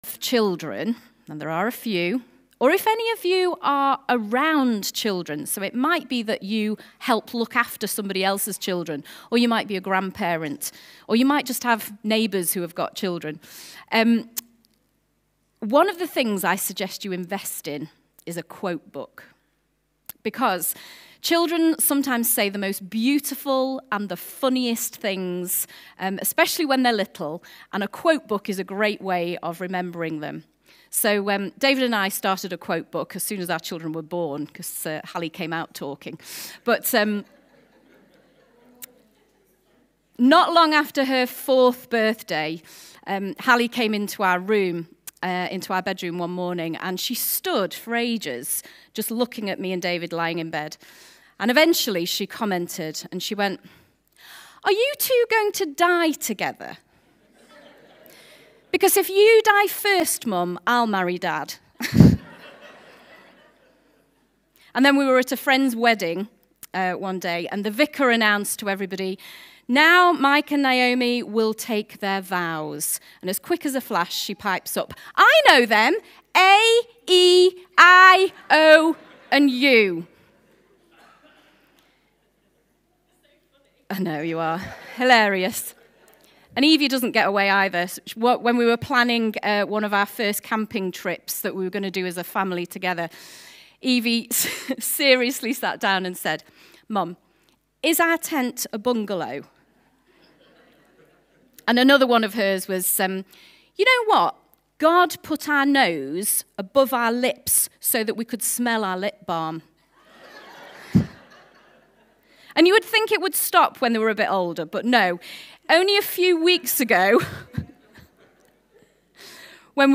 Short, child-friendly, talk about carrying the yoke of Jesus